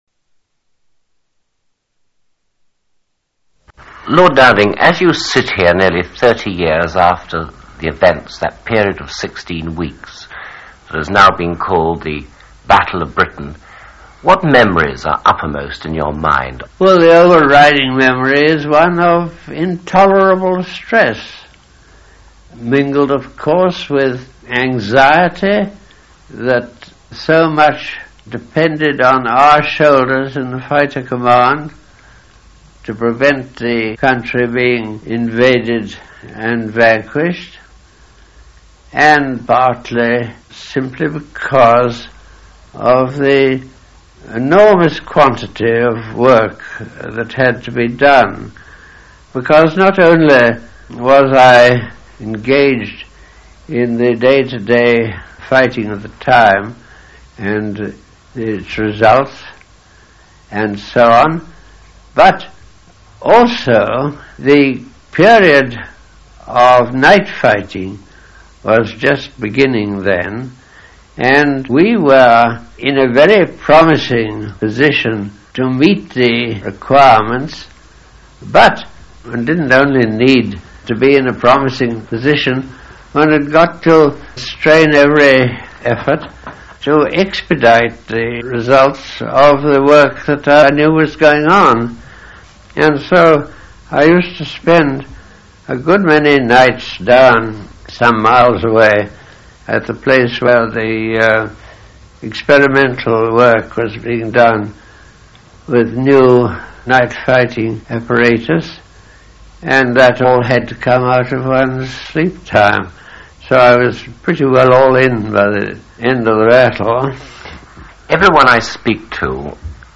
Air Chief Marshal Lord Dowding Click here to hear Air Chief Marshal Lord Dowding talking about the battle of britian.